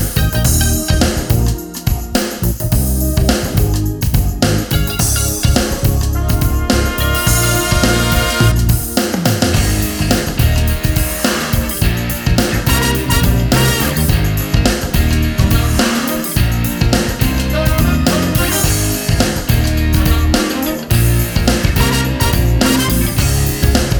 no Backing Vocals R'n'B / Hip Hop 4:29 Buy £1.50